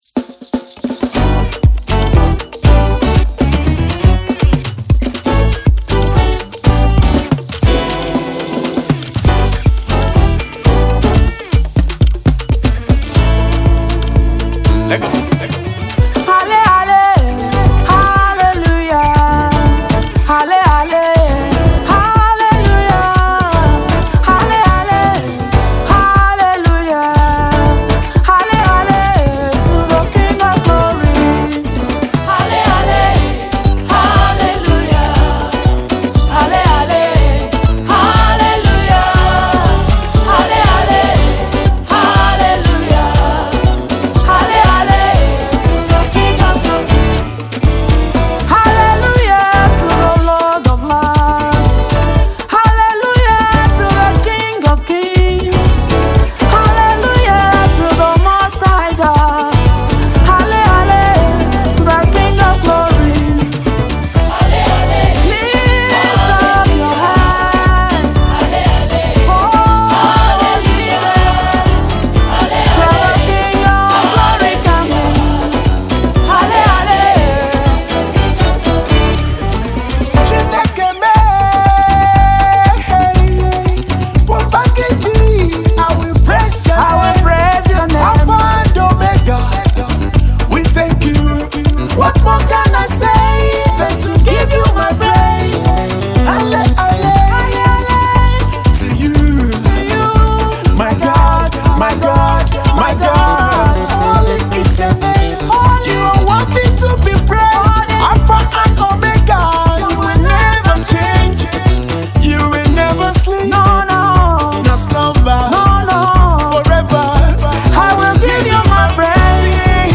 Gospel
can best be described as a sound of victory song